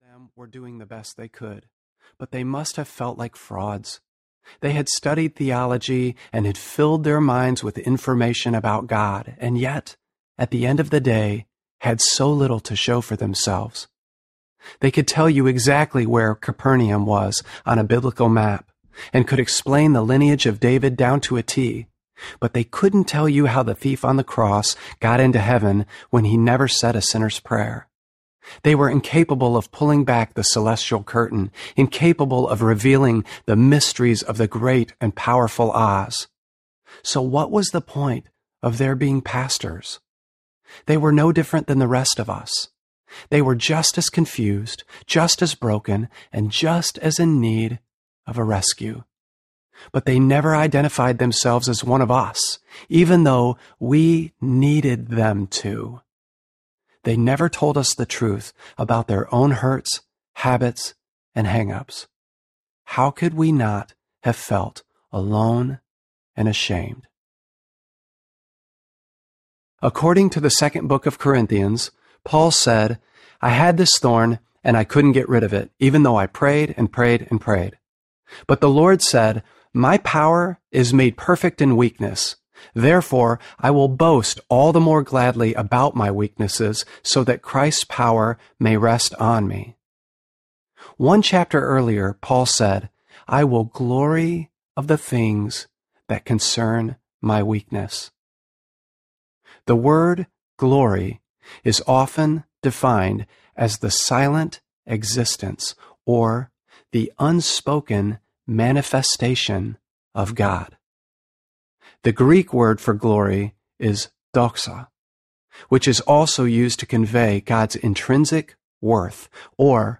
Finding God in the Ruins Audiobook